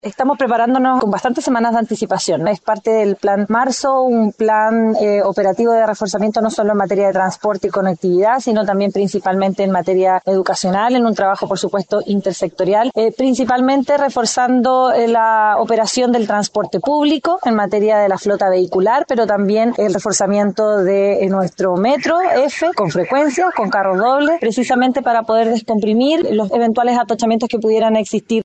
En ese sentido, la seremi Vocera de Gobierno, María Fernanda Moraga, indicó que para evitar la congestión en el transporte público, se está reforzando la frecuencia de carros dobles en el metro.